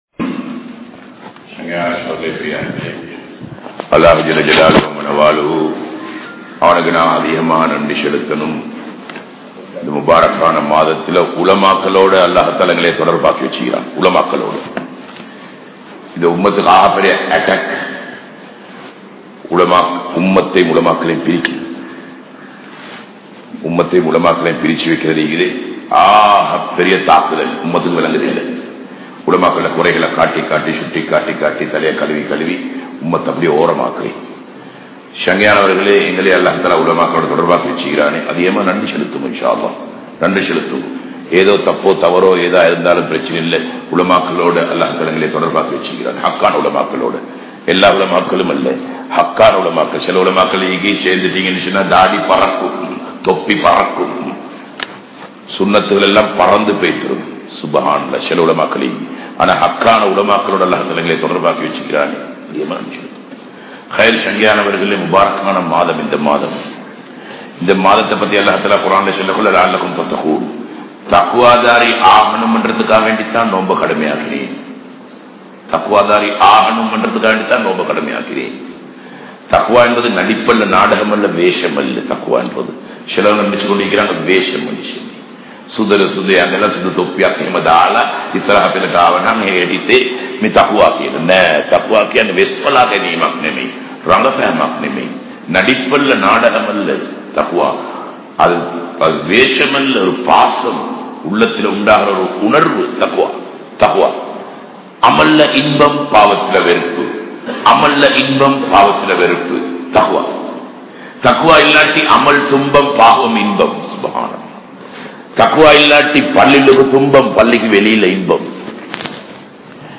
Ramalaanil Nadikkum Manitharhal (ரமழானில் நடிக்கும் மனிதர்கள்) | Audio Bayans | All Ceylon Muslim Youth Community | Addalaichenai
Masjithun Noor